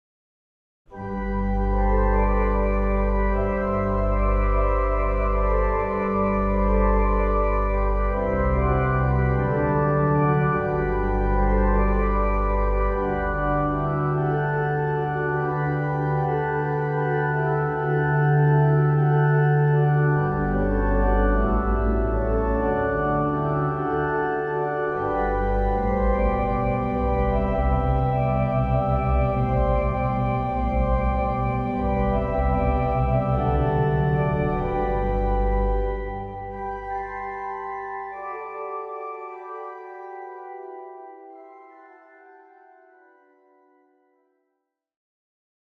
●リードオルガン 楽譜(手鍵盤のみで演奏可能な楽譜)